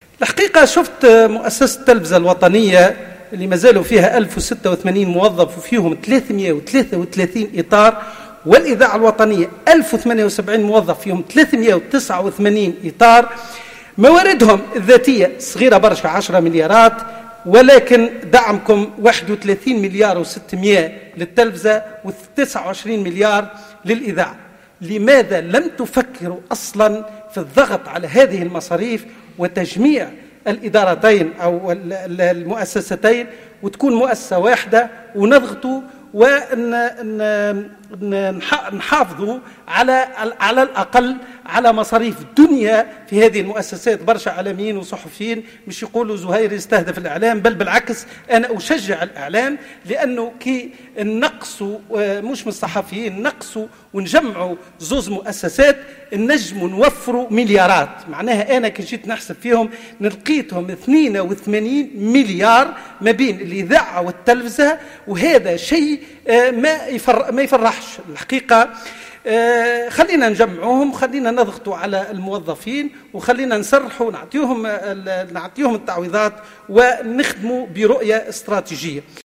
أكد النائب زهير مخلوف في كلمته على هامش الشروع في مناقشة مشروع ميزانية الحكومة لسنة 2021 أنه ينبغي العمل برؤية استراتيجية خاصة في ما يتعلق بالميزانية المرصودة لمؤسستي الإذاعة و التلفزة من خلال تجميع المؤسستين و تسريح بعض الإعلاميين مع اعطائهم التعويضات اللازمة.